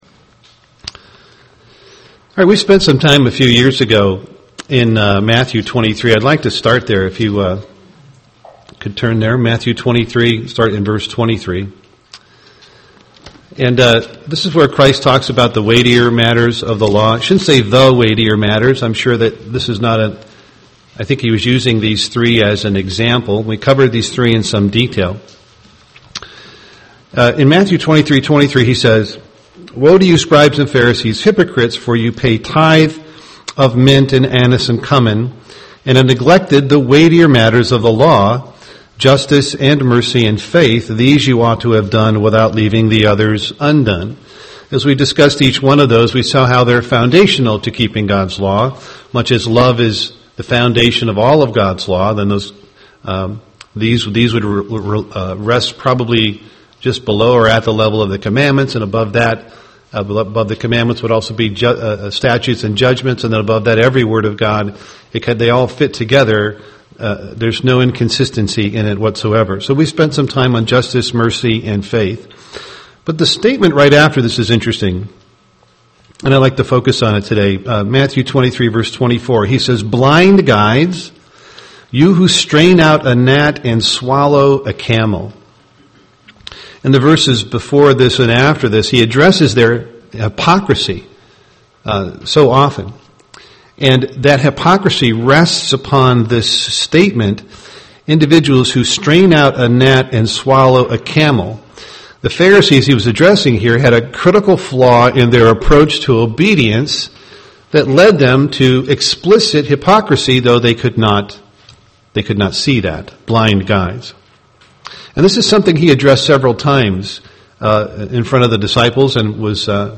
Given in Twin Cities, MN
UCG Sermon Holy Days self examination Studying the bible?